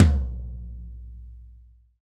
TOM TOM 87.wav